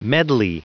Prononciation du mot medley en anglais (fichier audio)
Prononciation du mot : medley